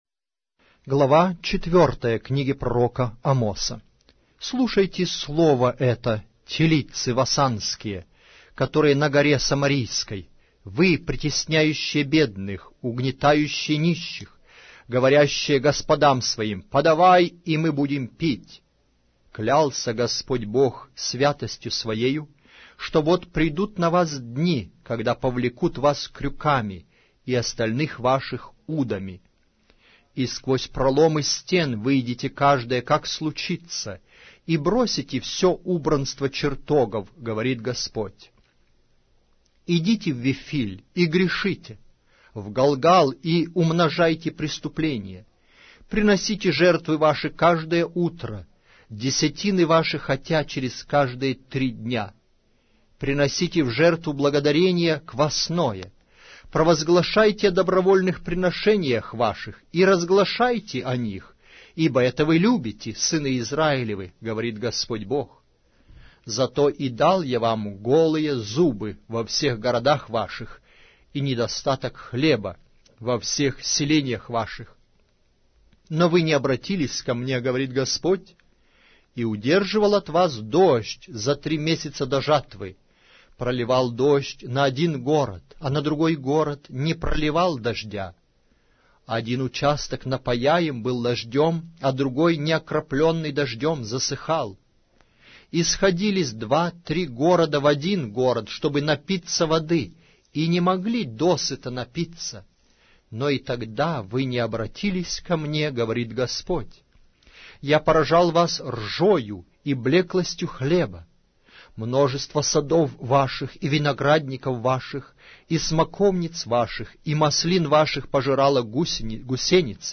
Аудиокнига: Амос